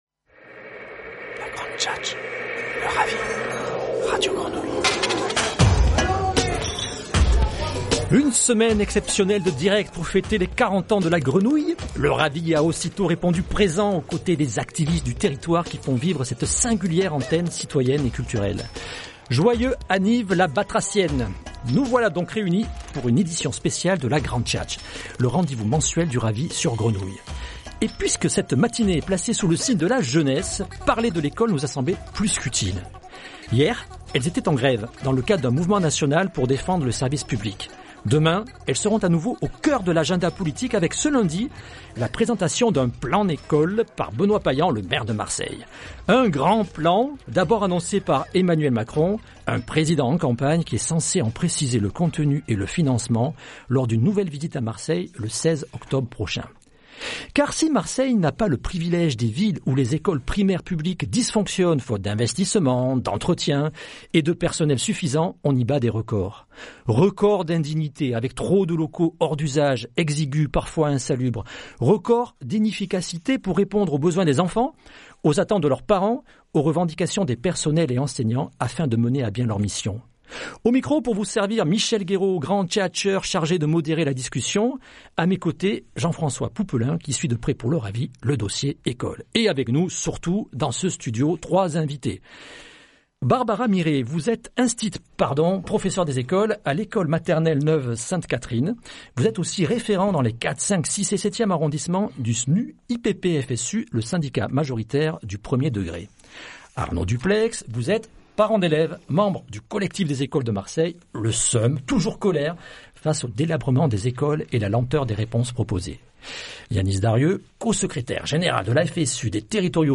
Trois invités et une heure d'antenne pour voir dans quelles conditions étudient nos minots.